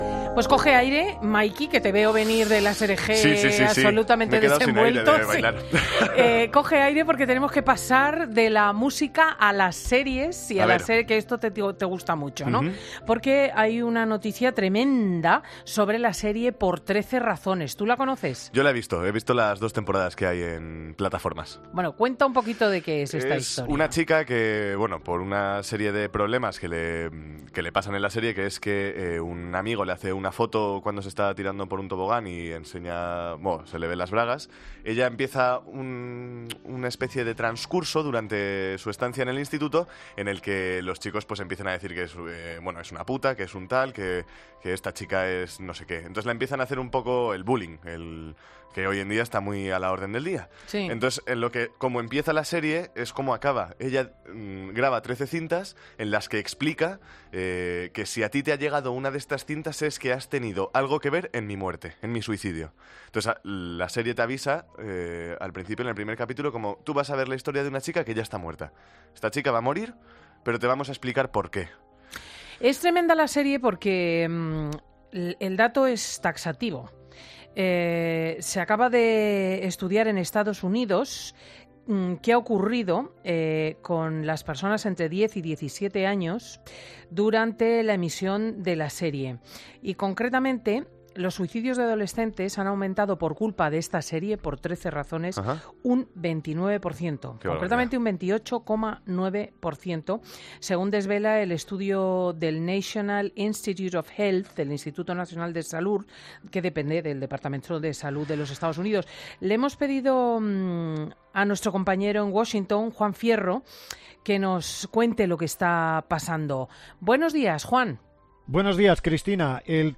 En Fin de Semana COPE, con Cristina López Schlichting, hemos preguntado al neuropsicólogo